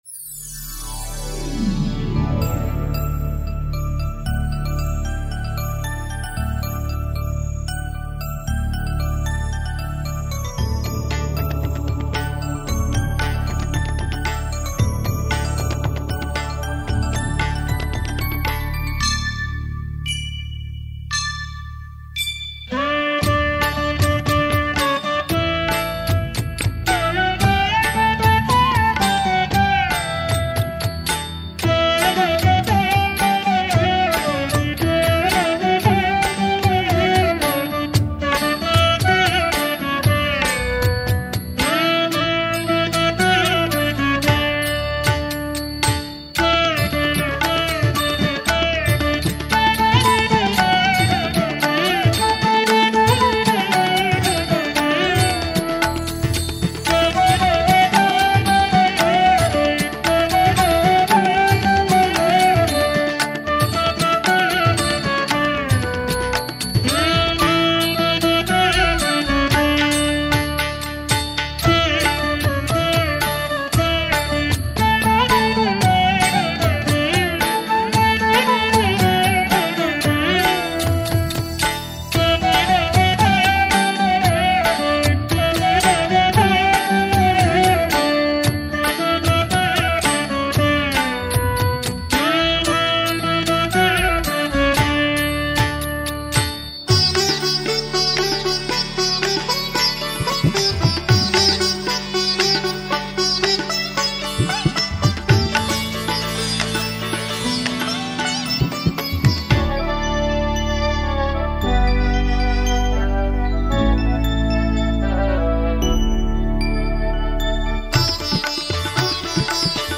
Bhajans:
Om Jai Jagadeesa Hare with Flute.mp3